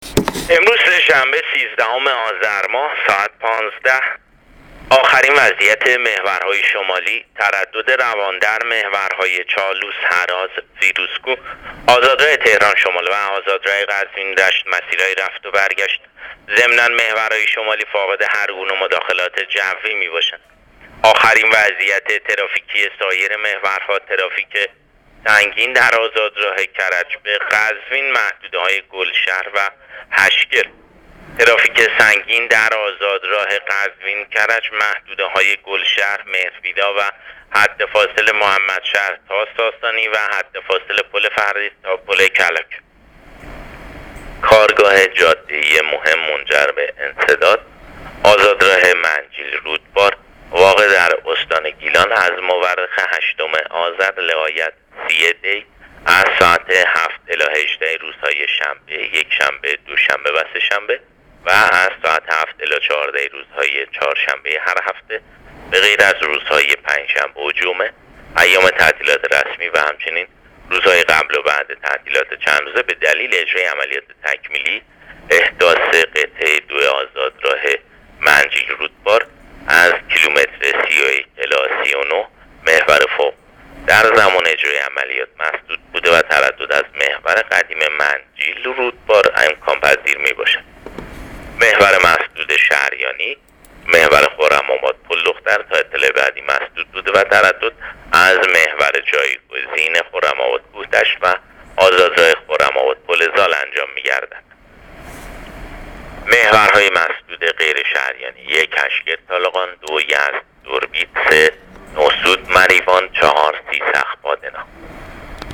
گزارش رادیو اینترنتی از آخرین وضعیت ترافیکی جاده‌ها تا ساعت ۱۵ سیزدهم آذر؛